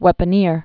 (wĕpə-nîr)